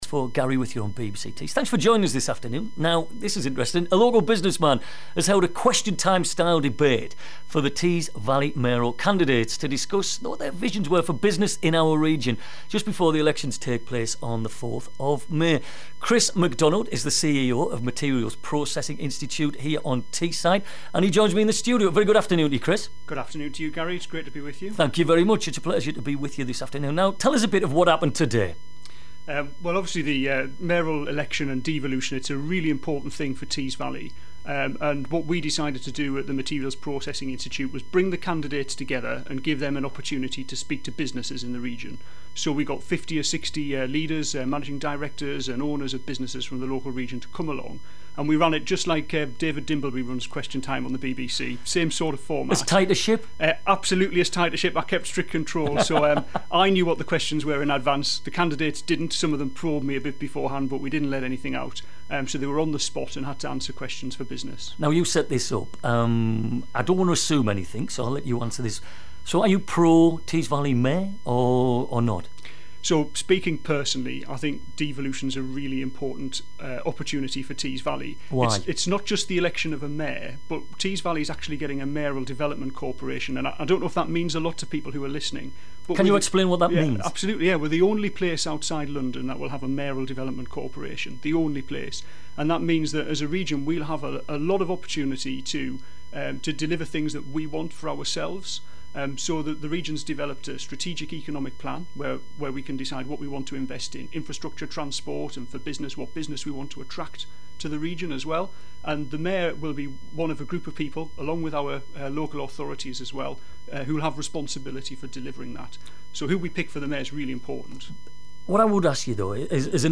BBC-Tees-interview-Mayoral-Question-Time-event.mp3